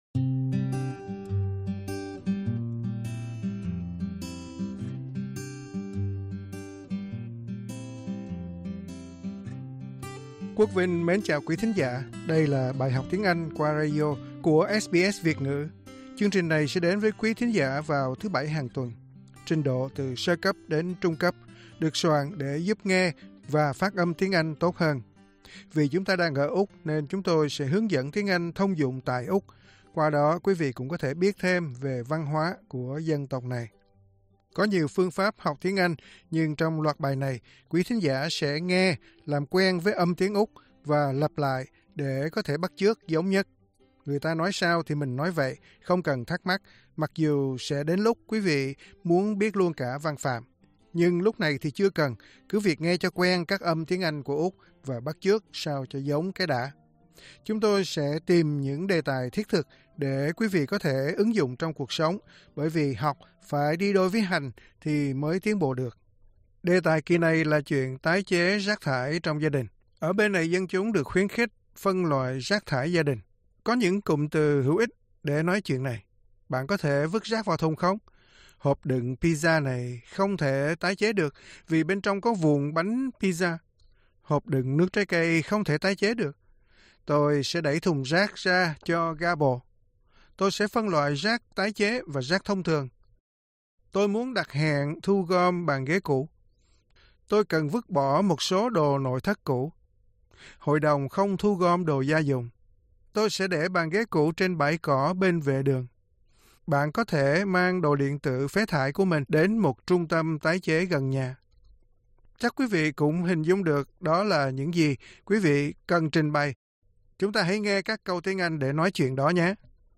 Cách phát âm /j/ và /w/.